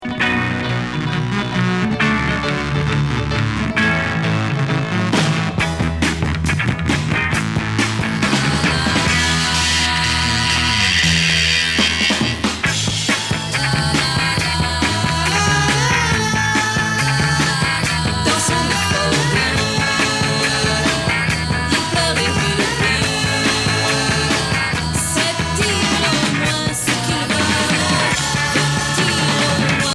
Pop psychédélique